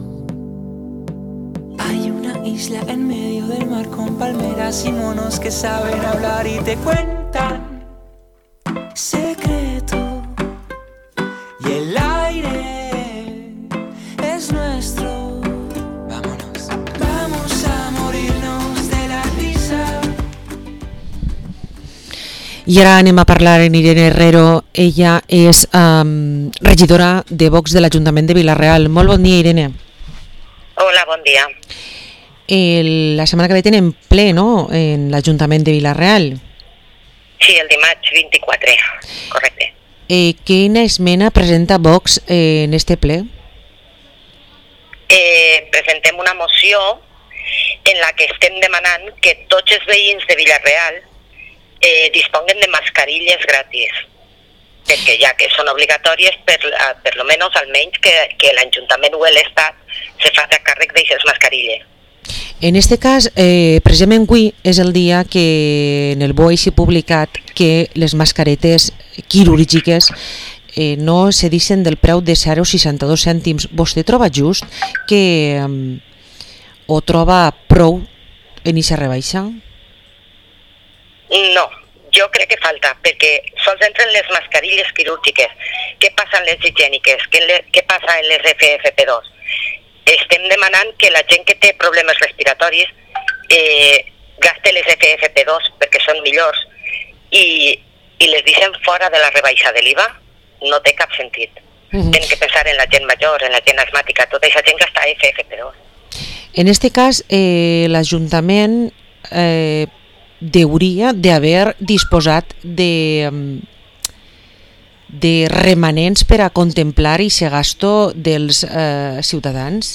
Entrevista a Irene Herrero, concejala de VOX en el Ayuntamiento de Vila-real